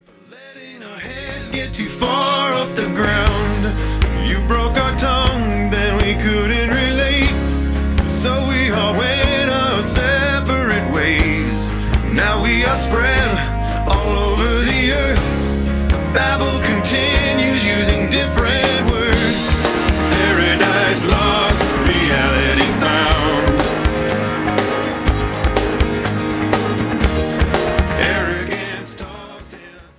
hooky intellectual rock
gorgeous, introspective liturgical modern standards